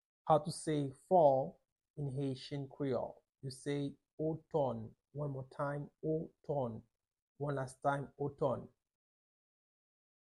Listen to and watch “otòn” audio pronunciation in Haitian Creole by a native Haitian  in the video below:
1.How-to-say-Fall-in-haitian-creole-–-oton-pronunciation-.mp3